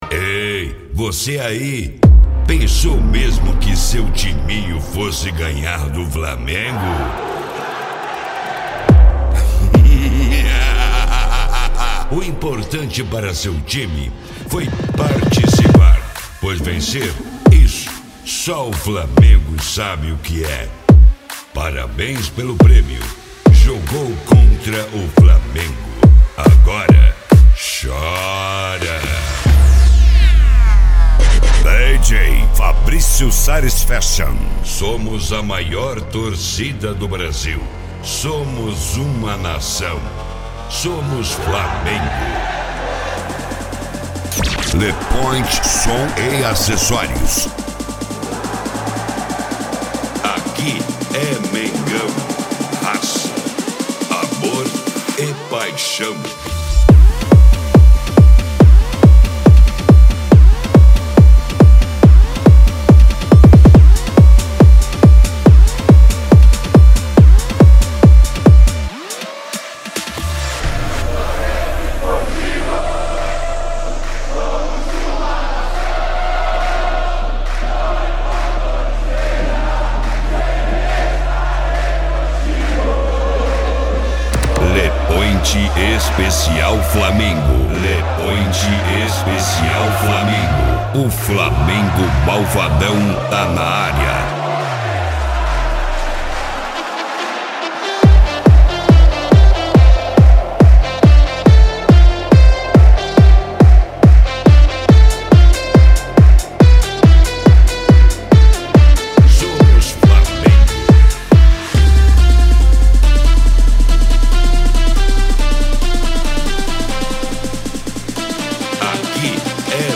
Funk
Mega Funk